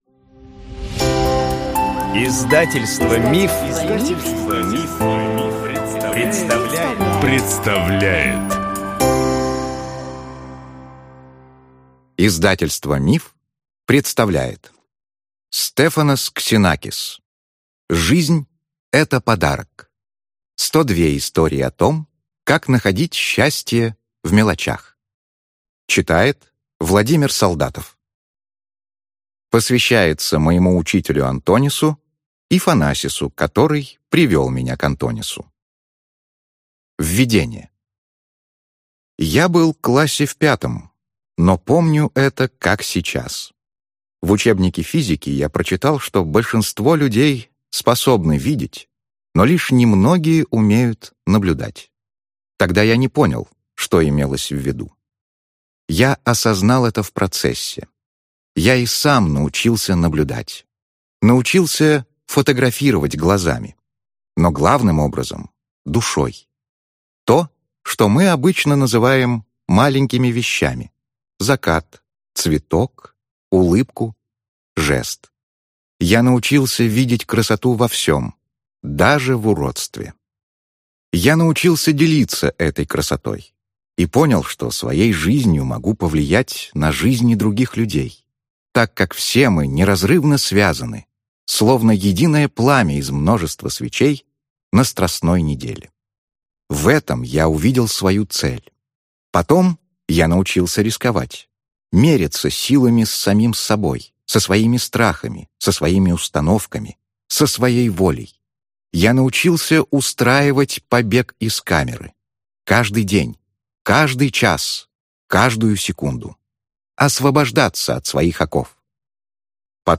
Аудиокнига Жизнь – это подарок. 102 истории о том, как находить счастье в мелочах | Библиотека аудиокниг